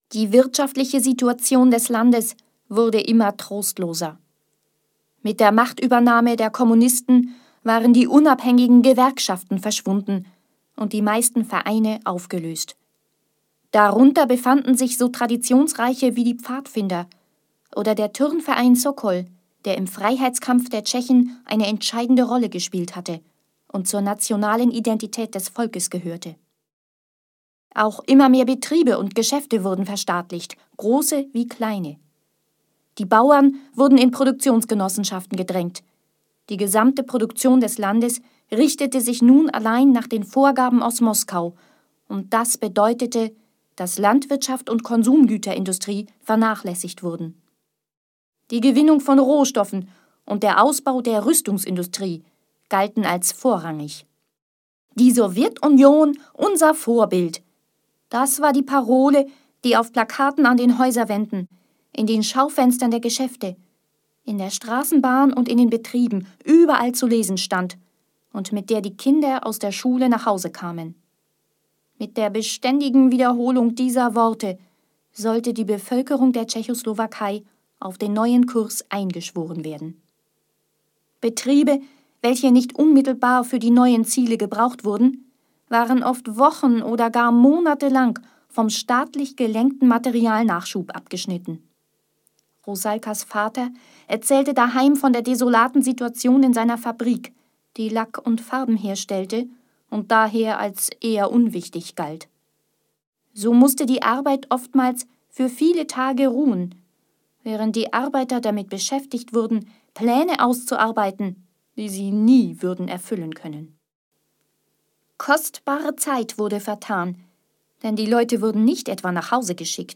Hier sind einige Beispiele, wie es klingt, wenn ich aus meinen Texten etwas vorlese.